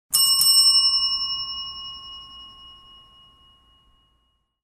order-notification.mp3